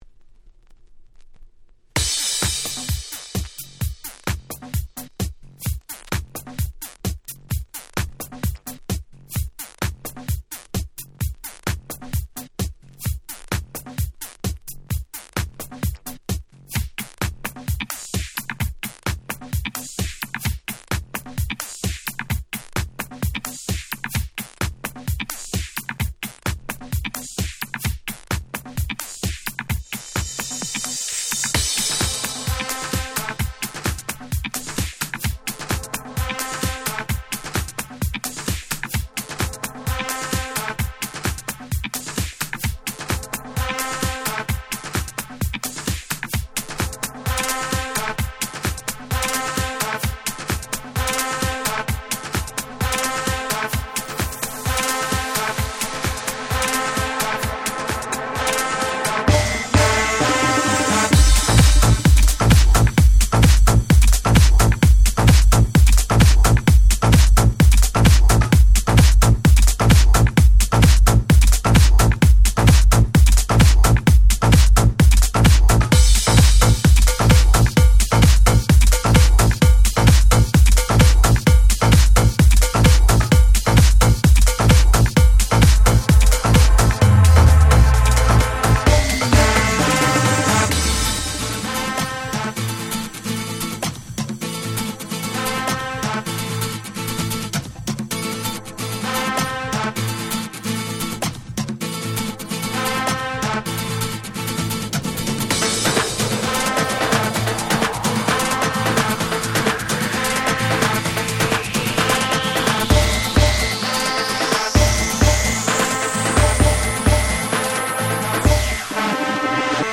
07' Very Nice Latin House / EDM !!
アゲアゲハウスキャッチー系